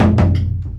Tom_8.wav